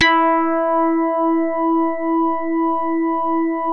JAZZ HARD E3.wav